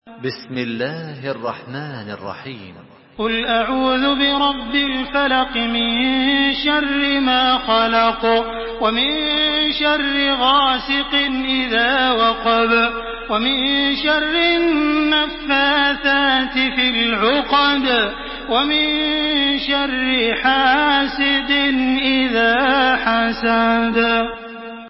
Surah Al-Falaq MP3 in the Voice of Makkah Taraweeh 1427 in Hafs Narration
Murattal